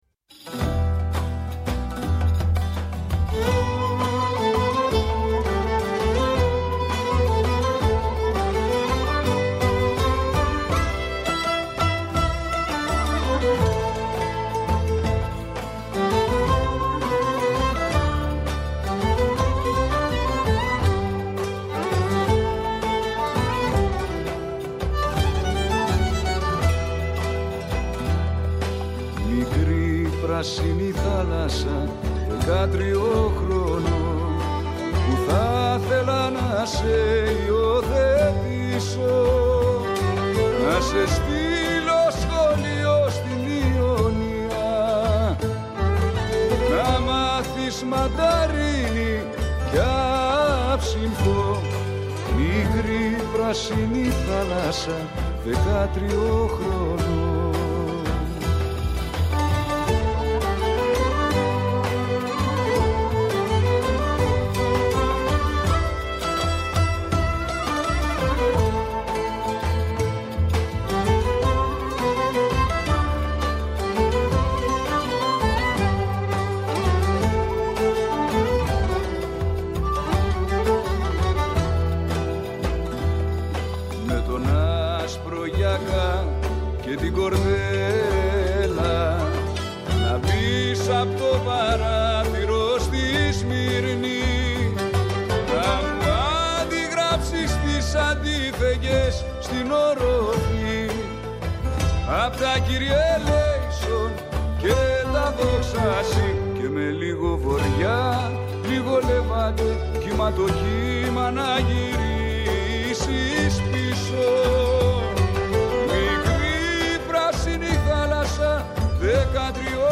Φιλοξένησε τηλεφωνικά